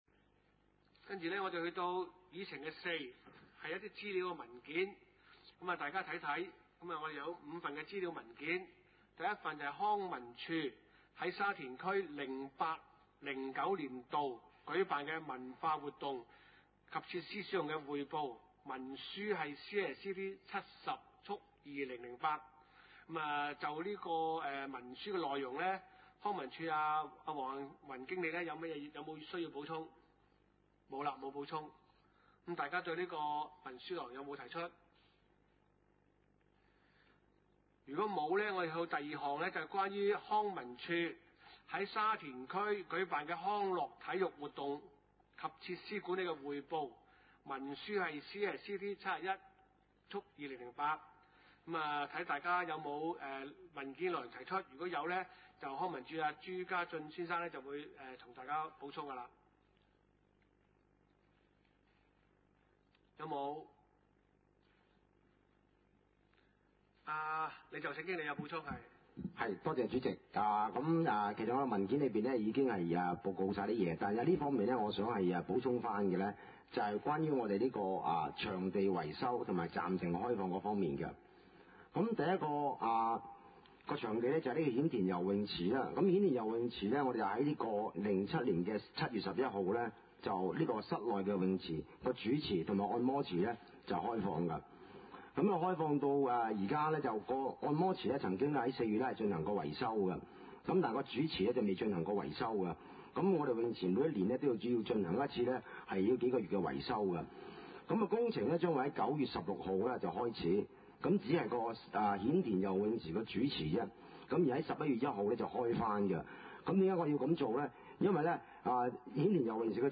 地點：沙田區議會會議室